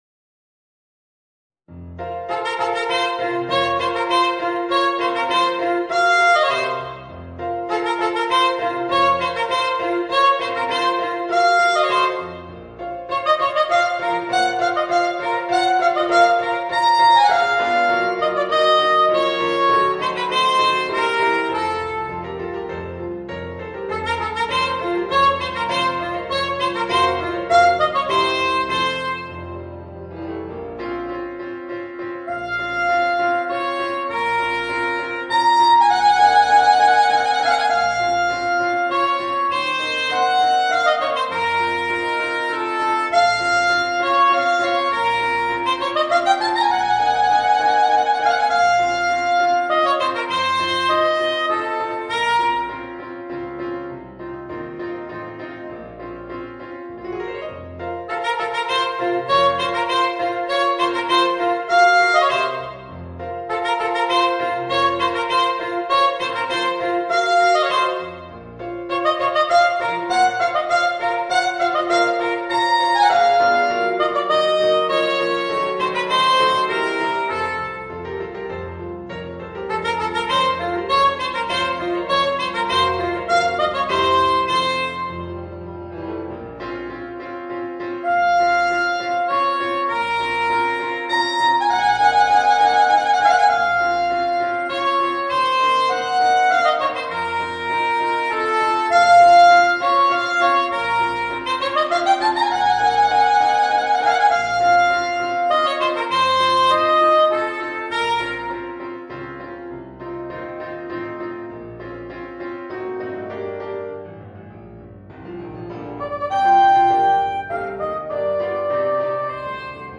Voicing: Soprano Saxophone and Piano